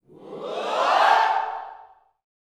SWHOOPS 2.wav